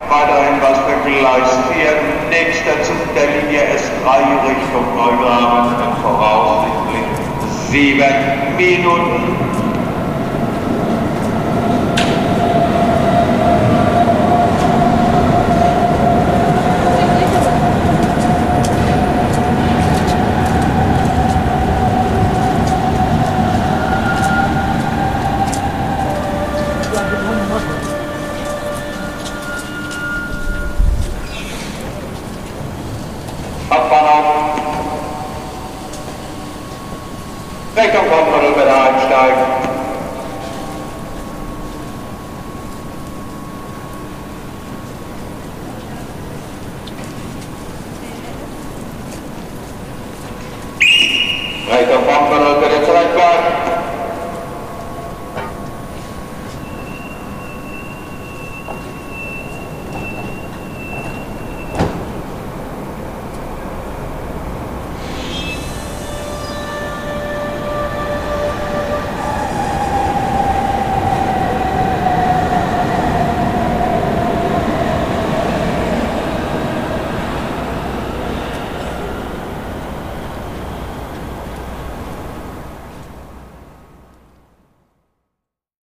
前にハンブルクに行ったときのVVVF音が耳慣れないタイプだった。
siemens製なんだとは思うが。
「次の電車は〜」アナウンス→制動→「発車します」→力行　の音
SBahn.mp3